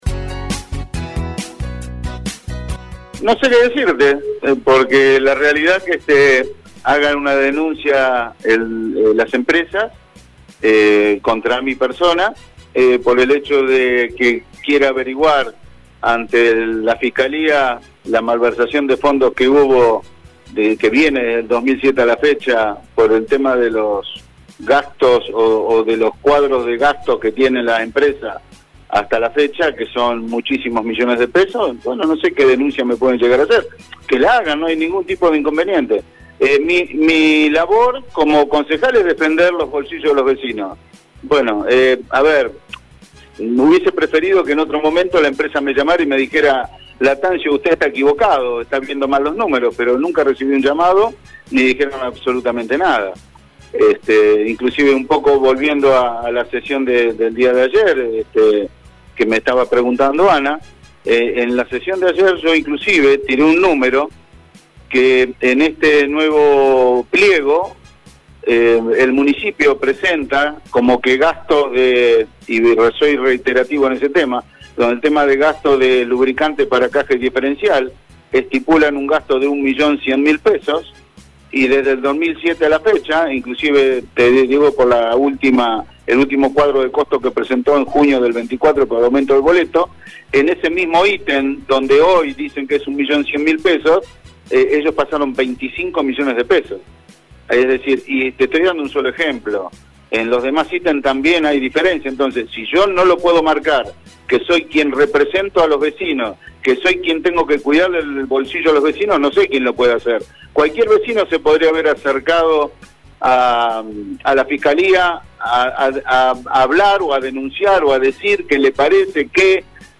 Así lo declaraba en LA MAÑANA DE HOY el concejal Omar Lattanzio: